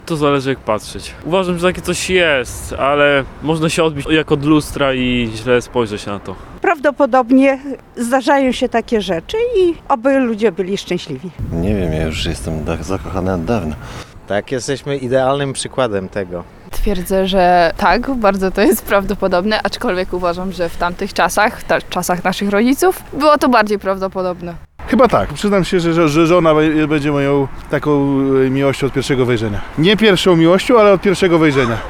Takie pytanie zadaliśmy przechodniom na ulicach Suwałk. Większość zapytanych uważa, że taka miłość może się zdarzyć, a niektórzy twierdzą, że sami zakochali się w pierwszej chwili.